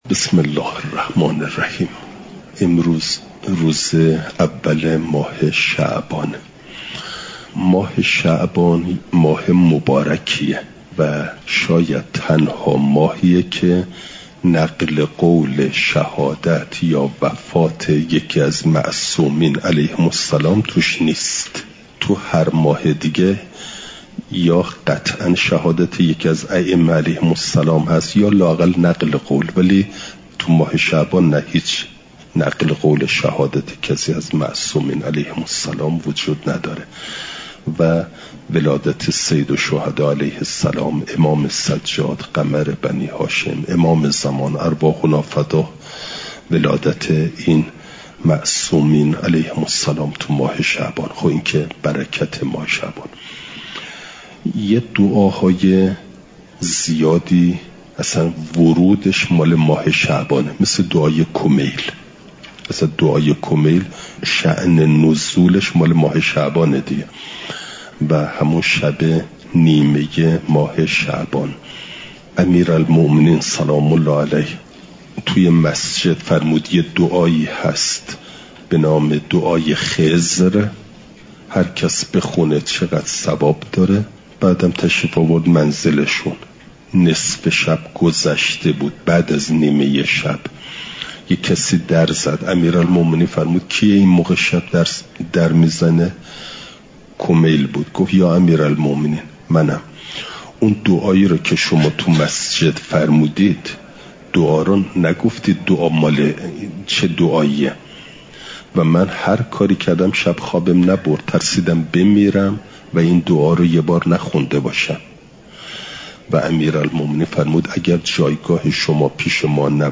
چهارشنبه ۱ بهمن‌ماه ۱۴۰۴، حرم مطهر حضرت معصومه سلام ﷲ علیها